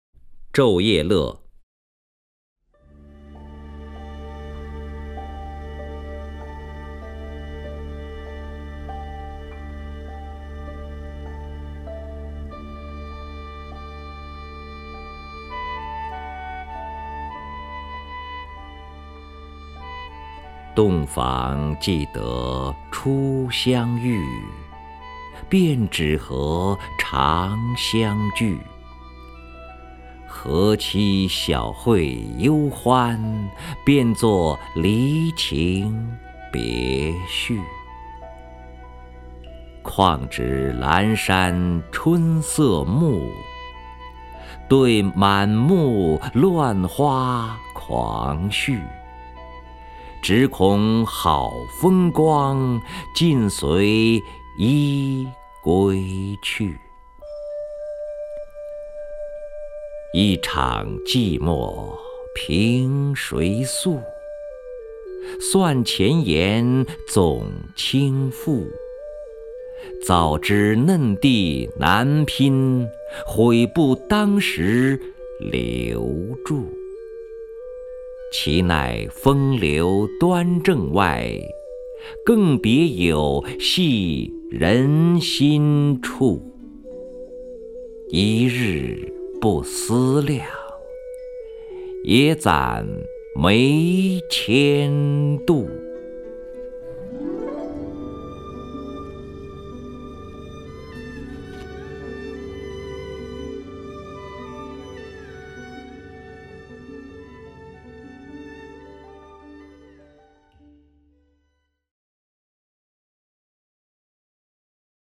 任志宏朗诵：《昼夜乐·洞房记得初相遇》(（北宋）柳永)
名家朗诵欣赏 任志宏 目录